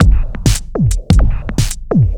Index of /musicradar/off-the-grid-samples/110bpm
OTG_Kit10_Wonk_110a.wav